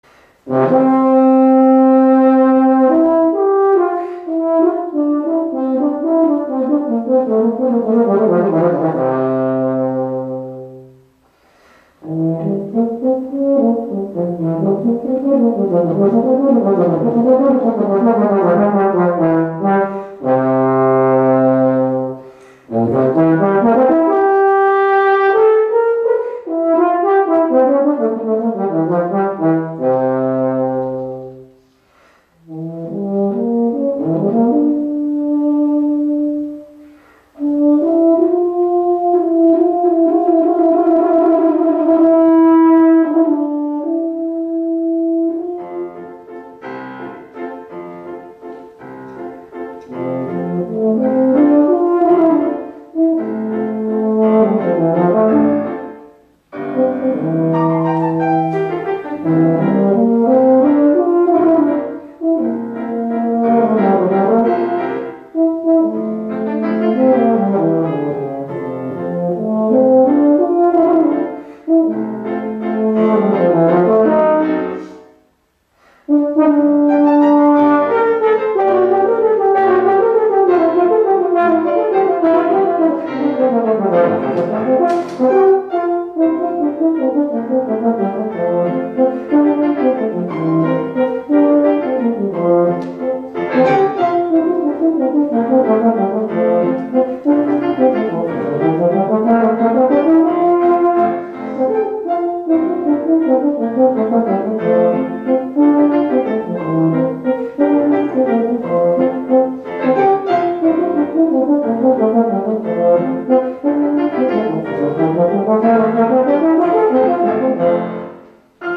La familia del Viento Metal se caracteriza por el sonido que viene generado por las vibraciones de los labios, producidas en el interior de una boquilla, por el efecto del aria introducida en el.
BOMBARDINO
Euphonium.mp3